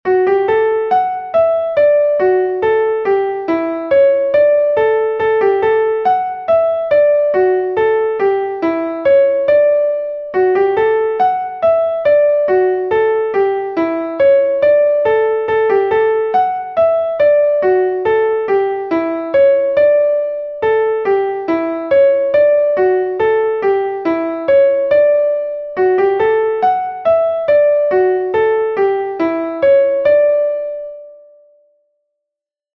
Text und Melodie: Volkslied (18./19. Jahrhundert)